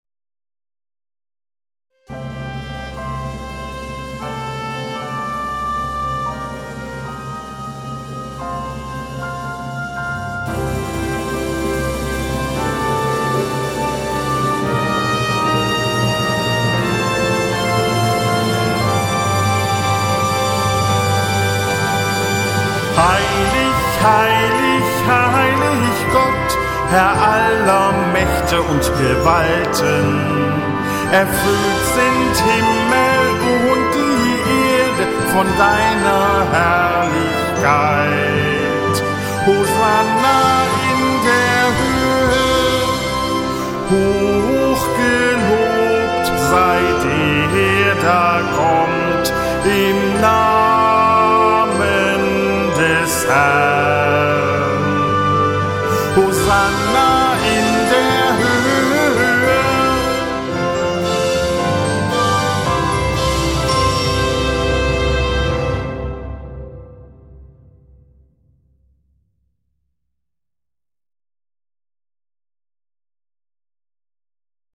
Dieses Stück verwendet den liturgischen Text des Sanctus und bettet ihn in eine Melodie. Das Besondere an dem Lied ist der Taktartwechsel in der Mitte.